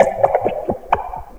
PERC FXLP1-L.wav